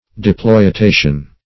Search Result for " deploitation" : The Collaborative International Dictionary of English v.0.48: Deploitation \Dep`loi*ta"tion\, n. [Cf. Exploitation , Deploy .]